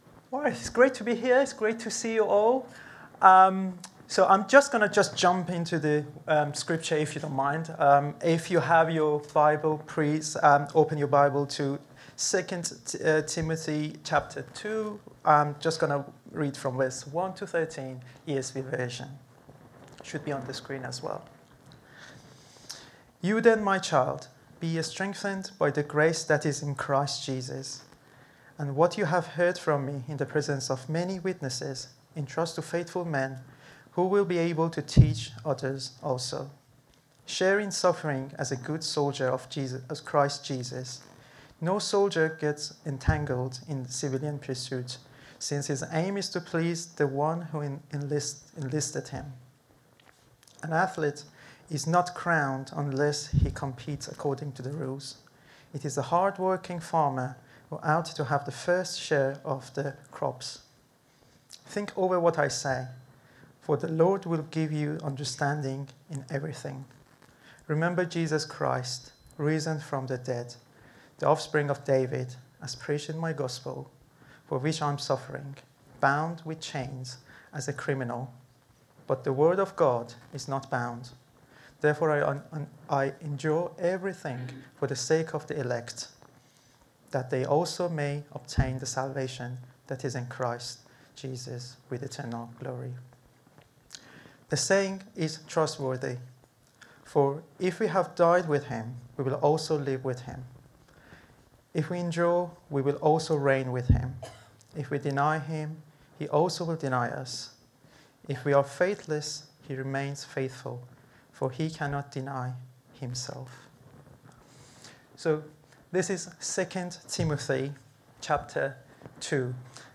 Download Suffering for the gospel | Sermons at Trinity Church